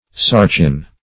Sarcin \Sar"cin\, n.